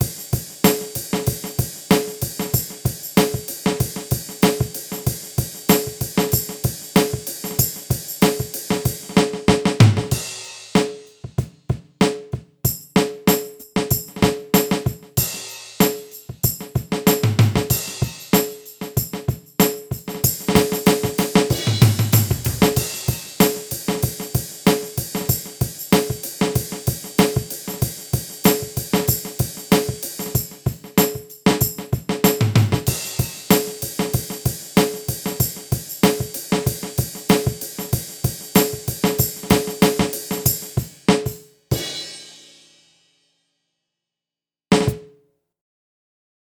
Csak érdekességként,megtaláltam egy régebbi vinyómon azokat a dob hangmintákat,amiket anno a dobosunk akusztikus motyójáról vettünk fel.közel 10 éve!
Csinyáltam belőle SF2 mintát!
Dob sampler.mp3